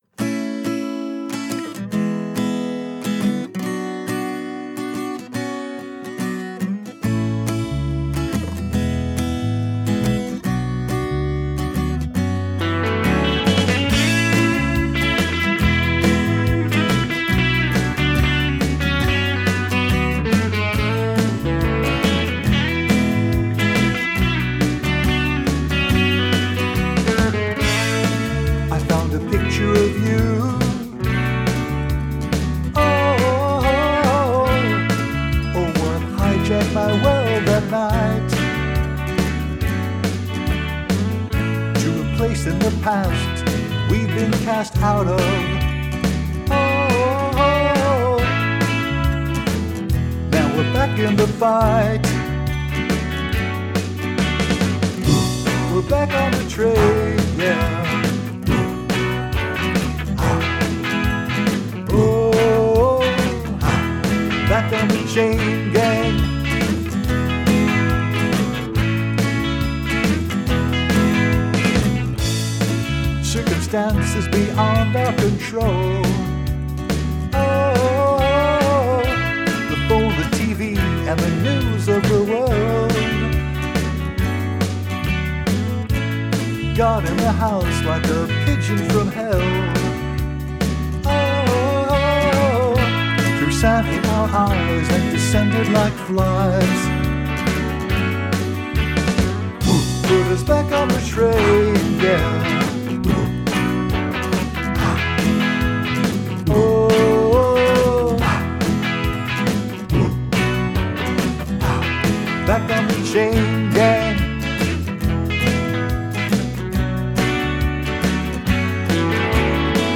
All of these were created remotely using Audacity software.